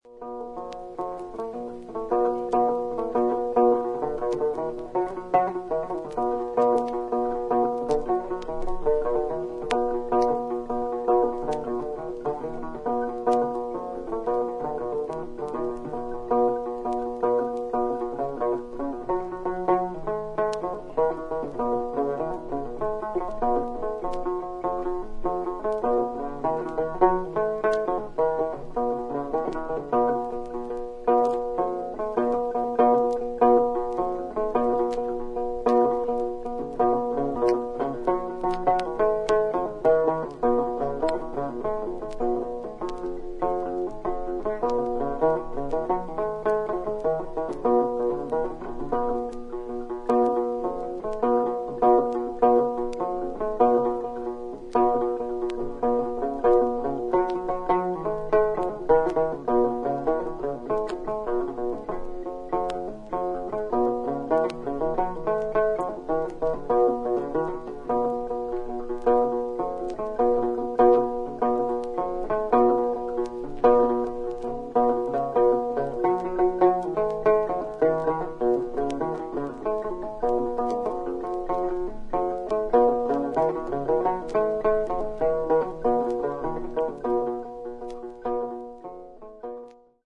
3曲目と4曲目に複数回プツッというノイズが入ります。
A4 Lute Solo